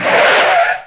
SKID1.mp3